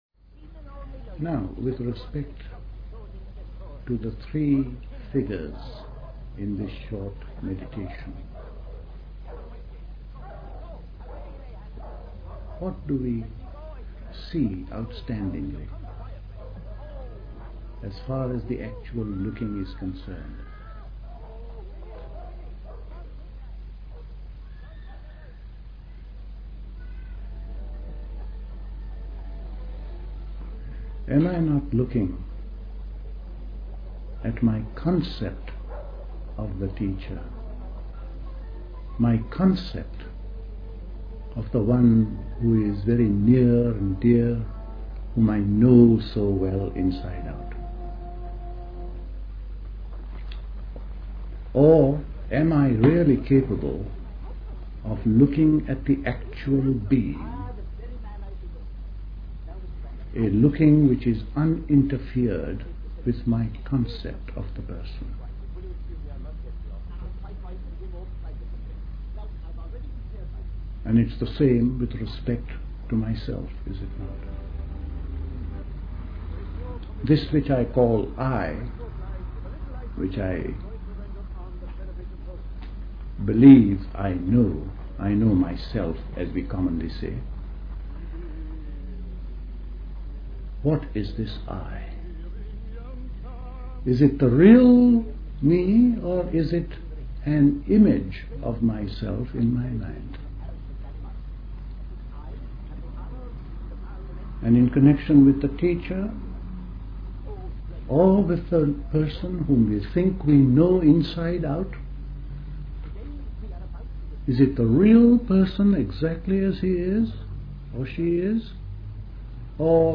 at Dilkusha, Forest Hill, London on 31st August 1969